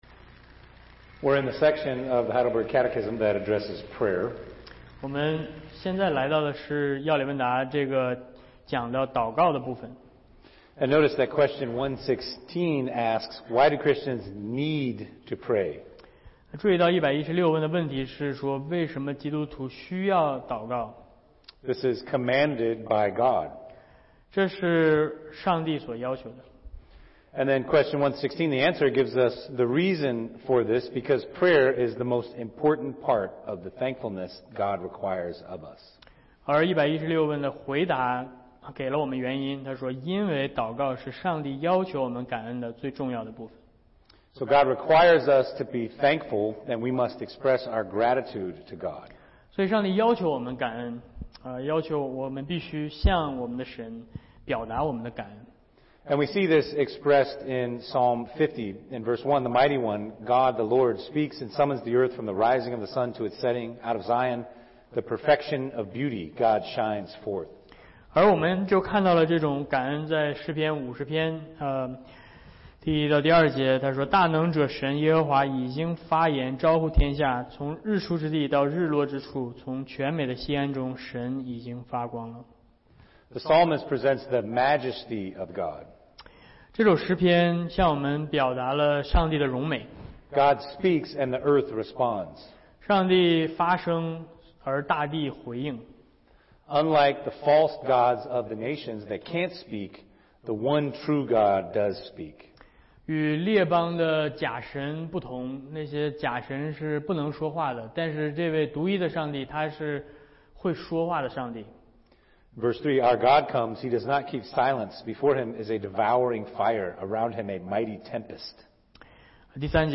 主日学课程